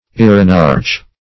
Search Result for " irenarch" : The Collaborative International Dictionary of English v.0.48: Irenarch \I"re*narch\, n. [L. irenarcha, irenarches, Gr.